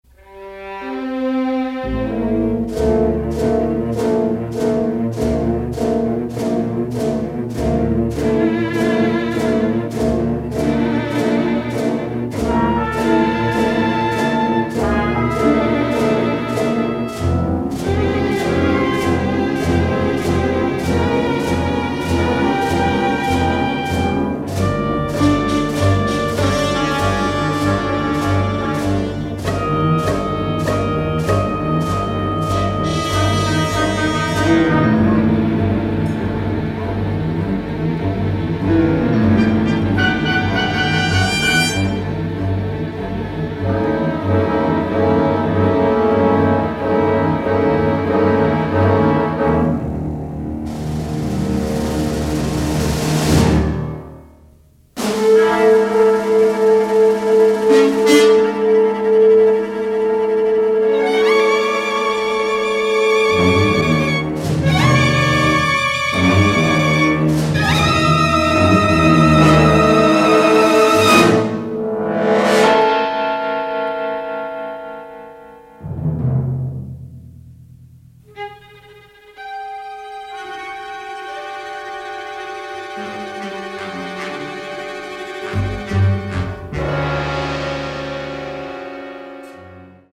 remarkable orchestral score